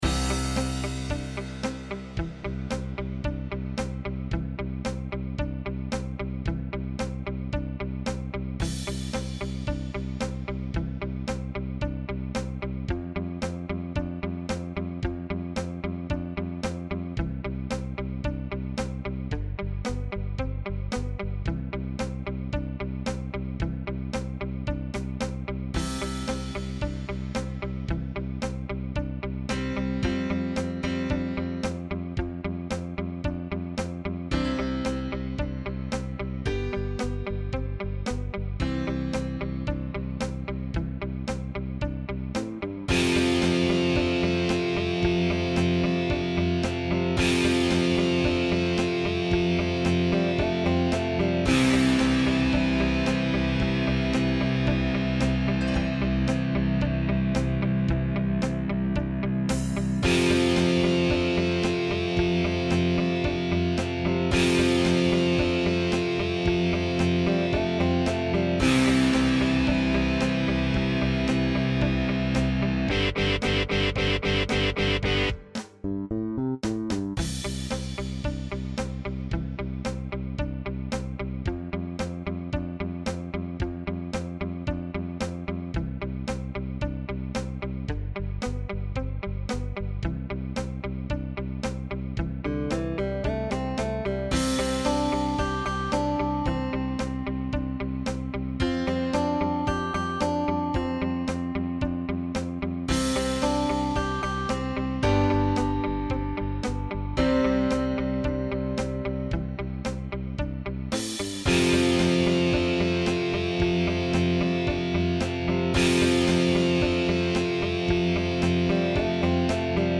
line